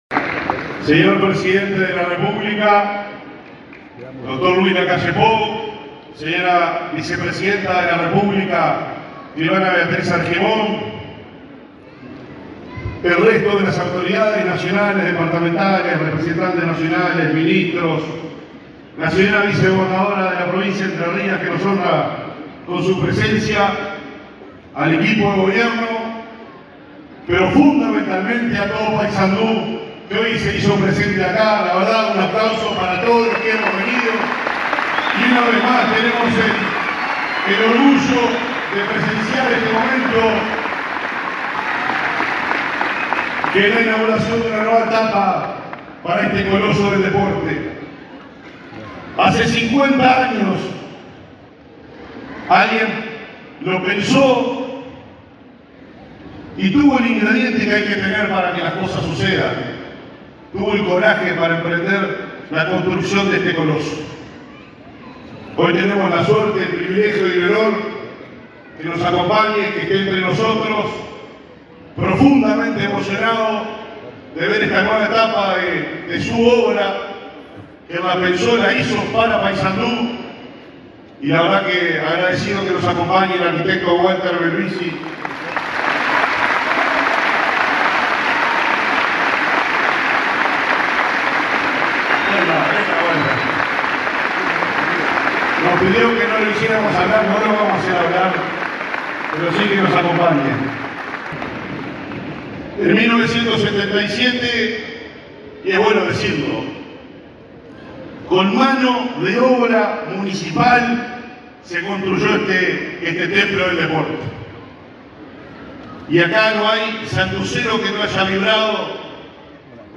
Palabras del intendente de Paysandú, Nicolás Olivera
El intendente de Paysandú, Nicolás Olivera, se expresó durante la inauguración de la reforma del estadio 8 de Junio, que fue convertido en un complejo